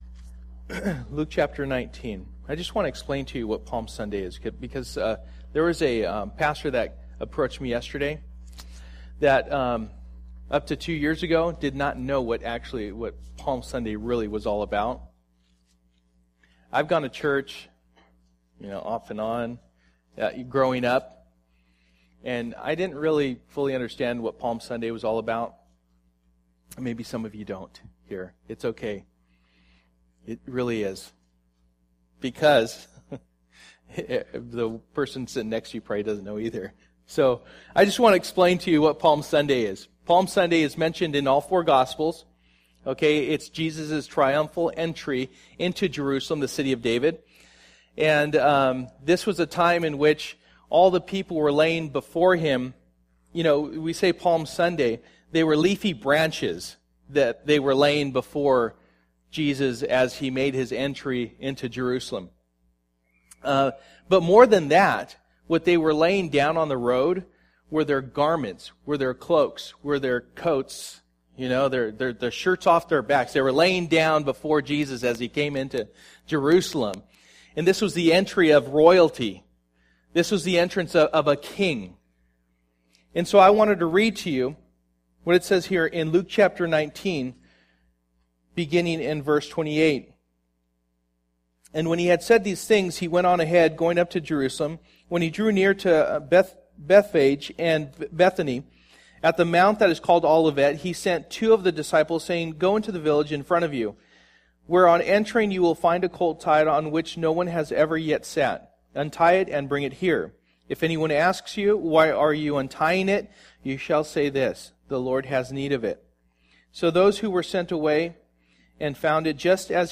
After God's Heart Passage: Psalm 150:1-6 Service: Sunday Morning %todo_render% « After God’s Heart